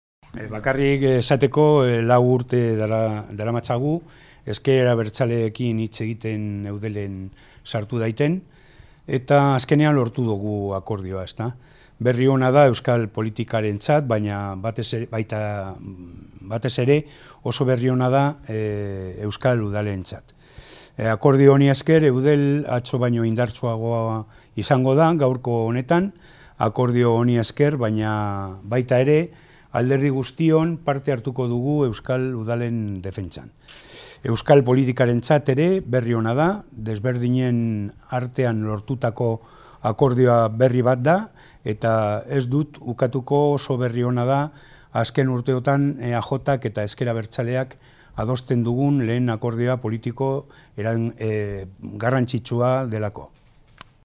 Sabin Etxean hedabideei eskainitako adierazpenetan